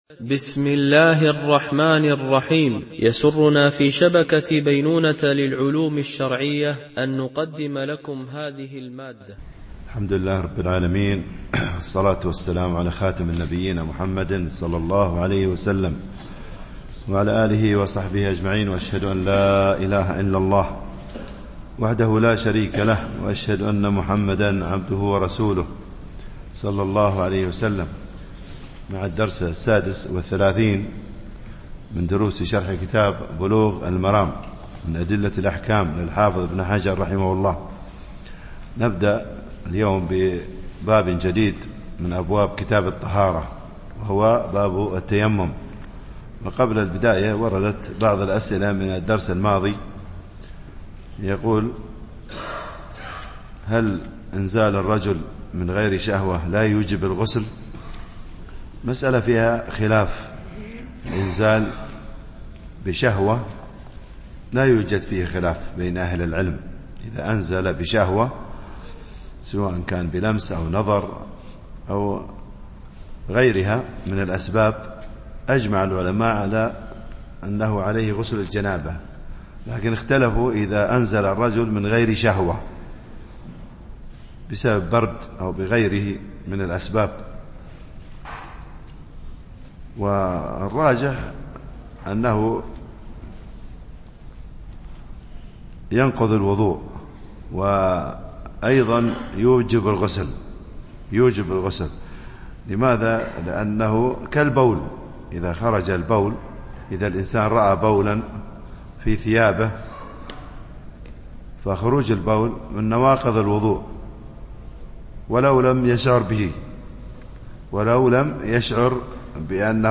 شرح بلوغ المرام من أدلة الأحكام - الدرس 36 ( كتاب الطهارة - باب التيمم، الحديث 119 - 121)